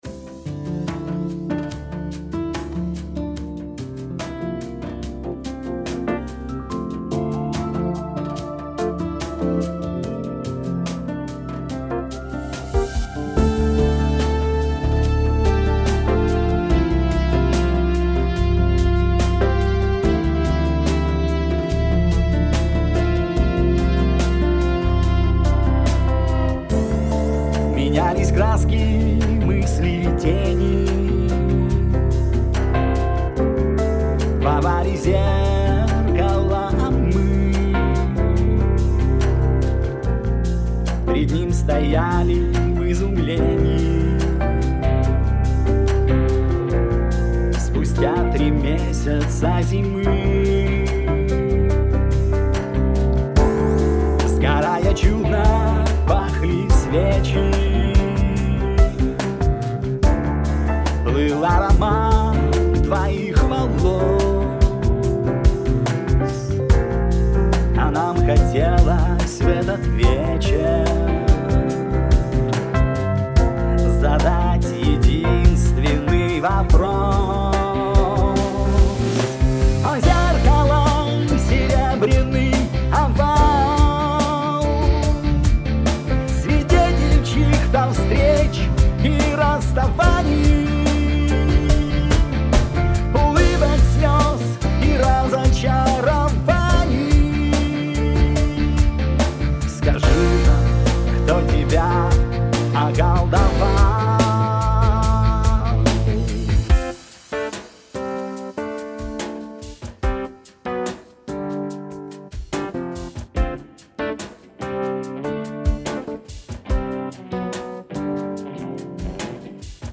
Песенка ( возможно немного нудноватая) на трех аккордах.
Когда-то сделал аранж.Сейчас решил свести. Вокалист-обычный врач,увлекающийся пением.